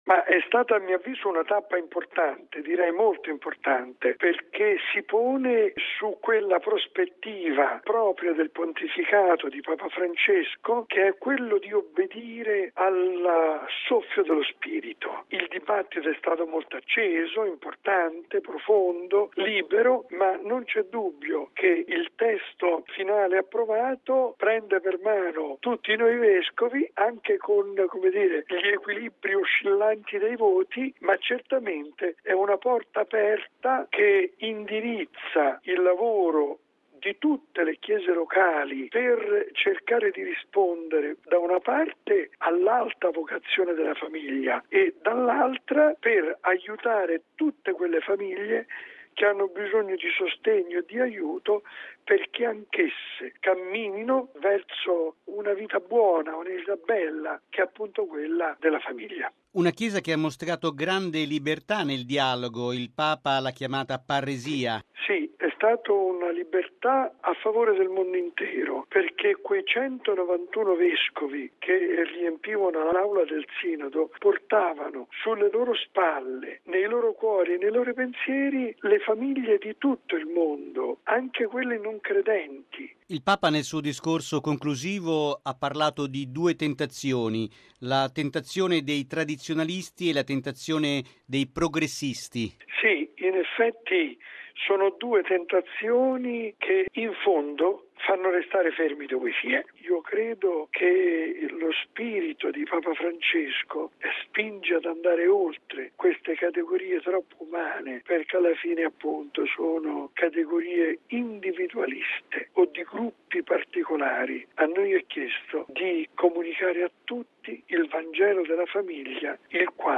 Ascoltiamo il commento di mons. Vincenzo Paglia, presidente del Pontificio Consiglio per la Famiglia